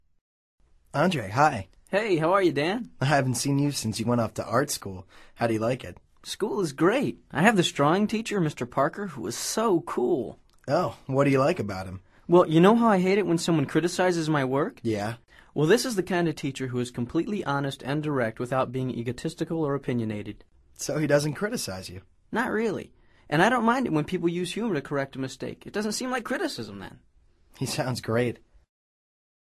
Escucha atentamente esta conversación entre Dan y Andre y selecciona la respuesta más adecuada de acuerdo con tu comprensión auditiva.